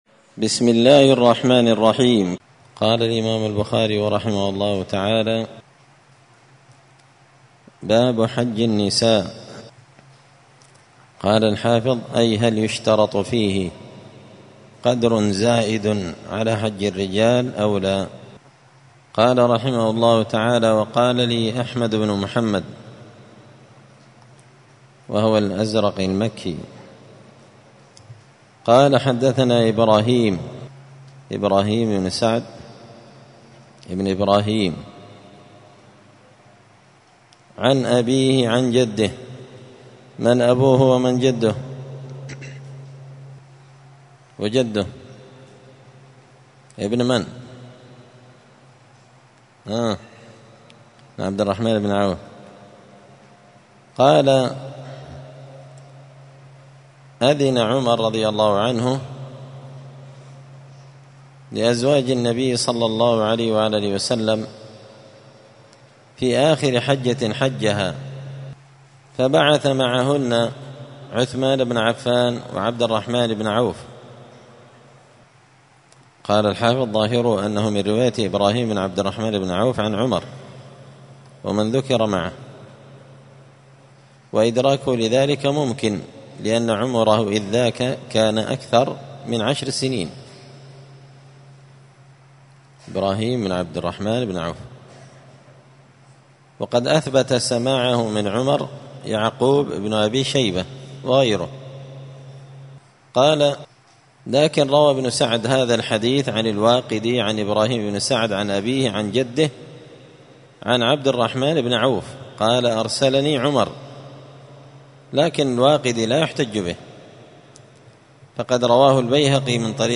مسجد الفرقان قشن المهرة اليمن 📌الدروس اليومية